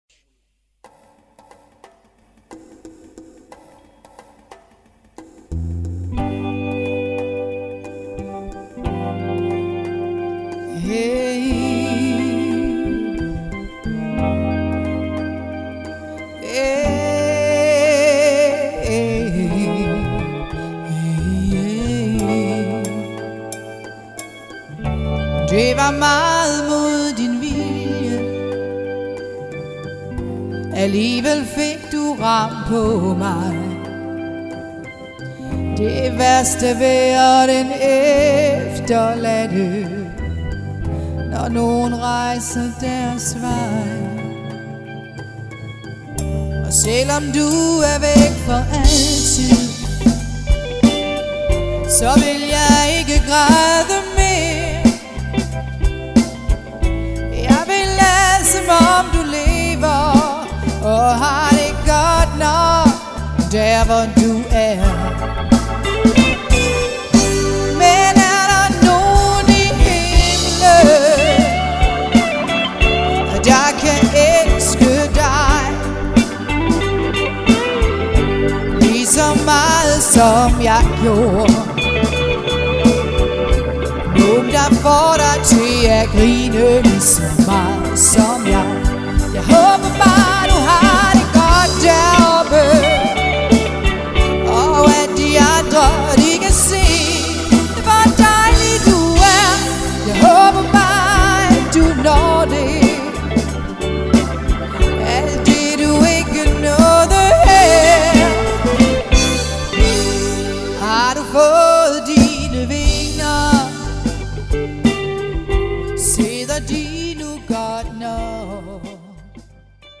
• Allround Partyband
• Duo eller trio